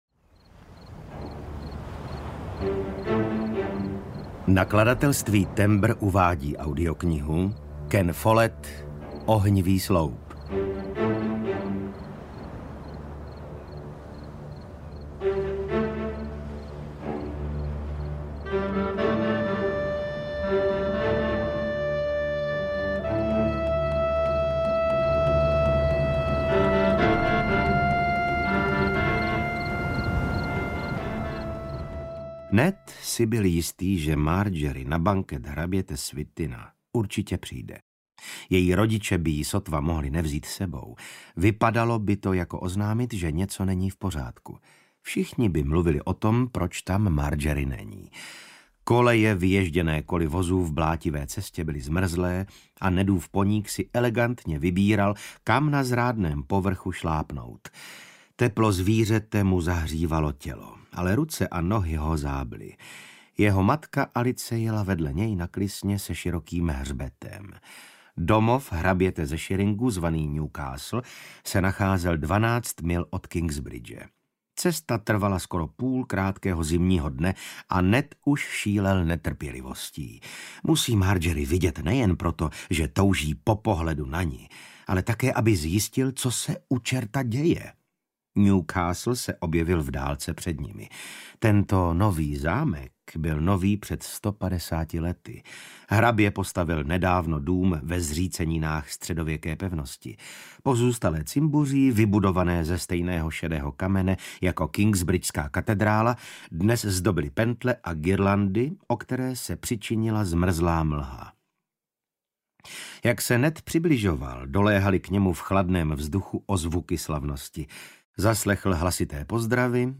Ohnivý sloup audiokniha
Ukázka z knihy
• InterpretVasil Fridrich